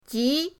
ji2.mp3